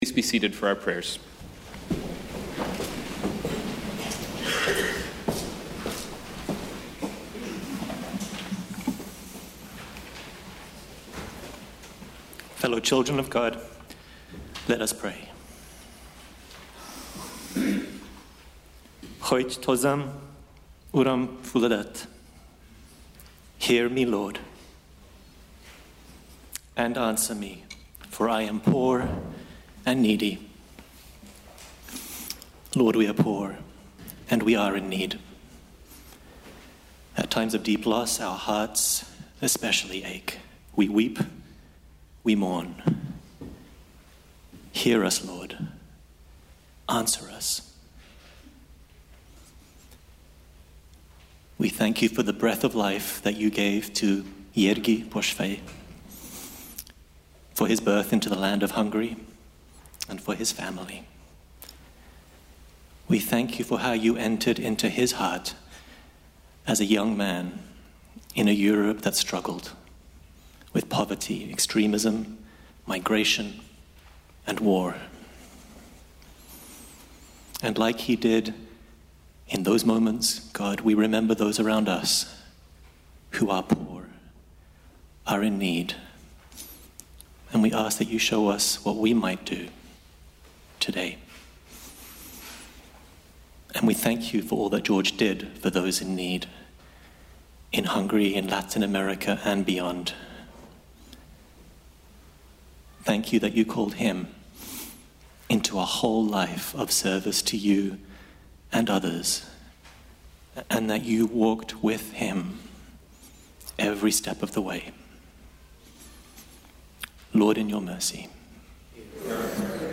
Prayers of Intercession:
prayersintercession.mp3